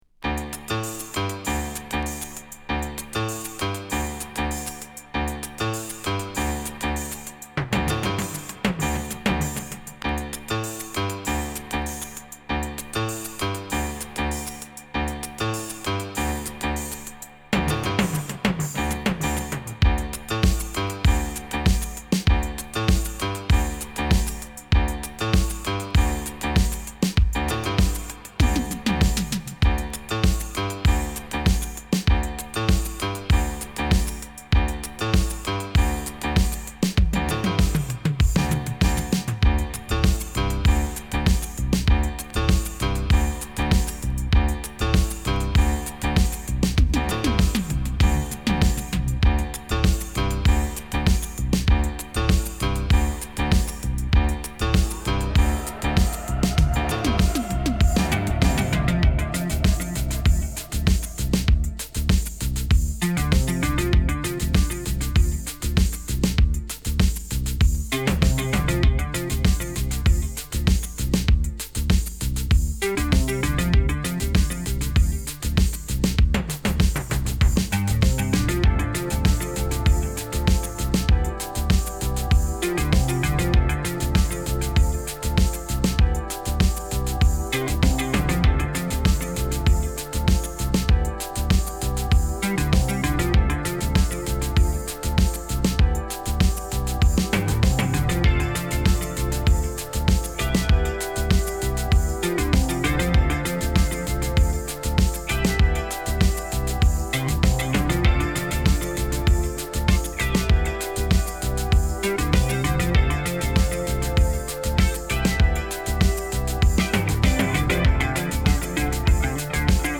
| エレガントなピアノを基調とした長尺のディープ・ハウス傑作。
エレガントなピアノを基調とした長尺のバレアリック・ディープ・ハウス傑作。
ドラマティックなサイドBも最高です。
Deep House